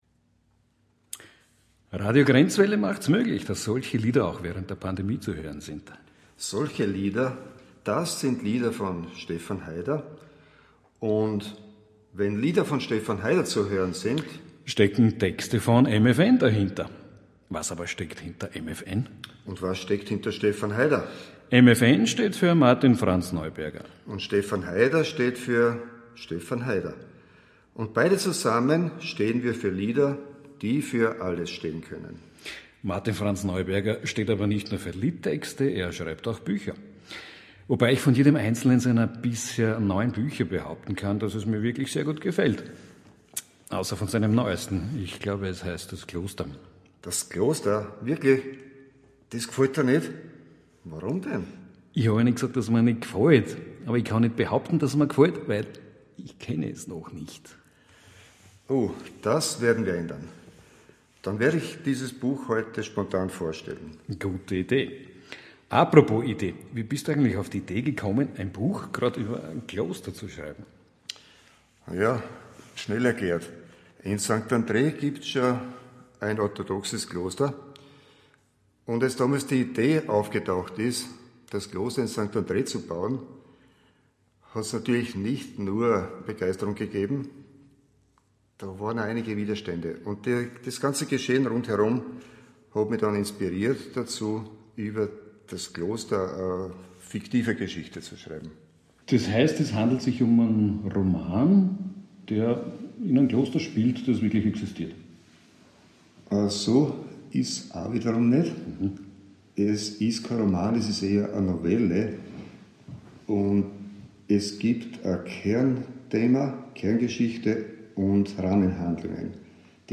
Es enthält einen Sendungsmitschnitt von „Radio grenzWelle“ vom 19.02.2021. Sie können die Datei unter diesem Link abrufen.